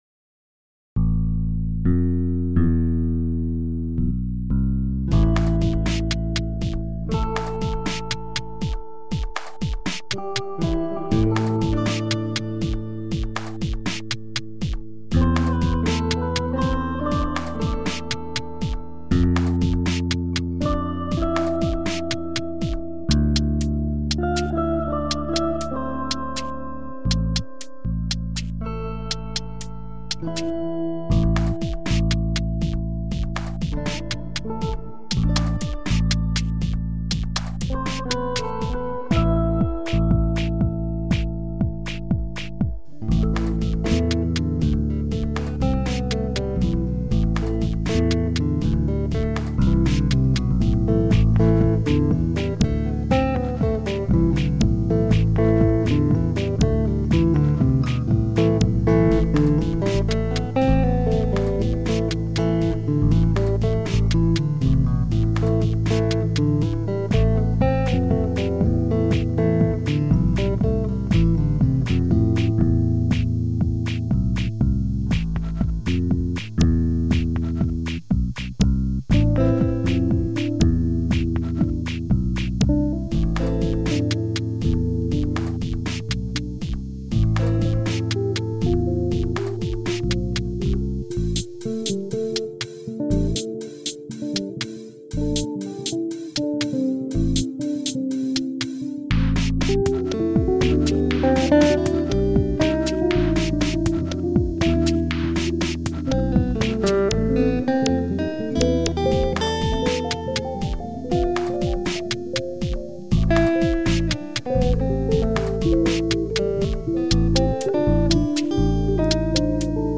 all Instruments